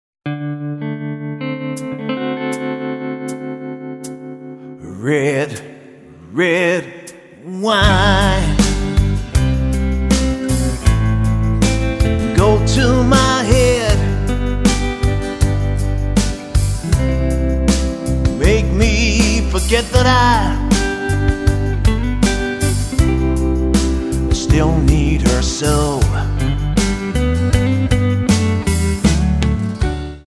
Tonart:G Multifile (kein Sofortdownload.